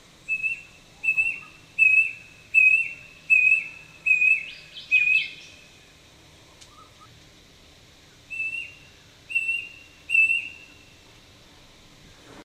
The call of the brush cuckoo
Brush_Cuckoo_song_Nov2007.ogg.mp3